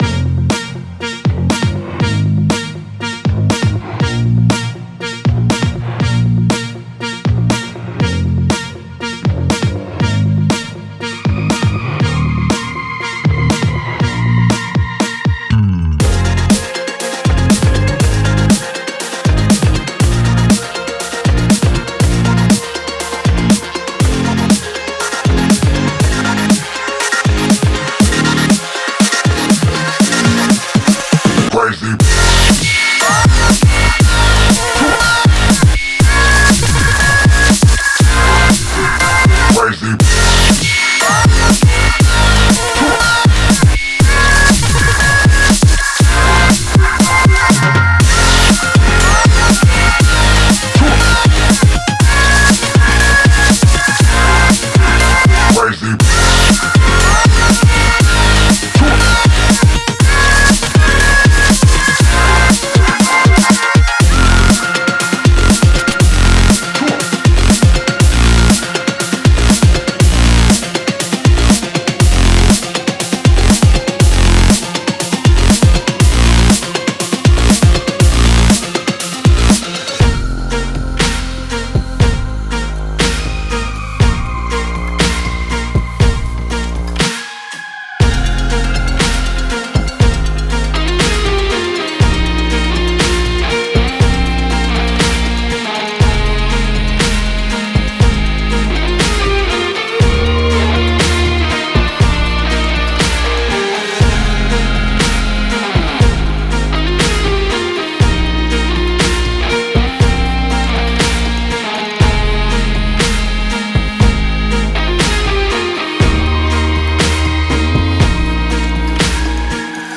Drum n' Bass EDM
I really tried to focus on the mixing aspect (blending of sounds, etc) for this track, while simultaneously emphasizing the bass and drum tracks, aligning the song with the aforementioned genre. I also snuck a small guitar solo around the middle of the song which I finally got right after about 50 takes.
dnb.mp3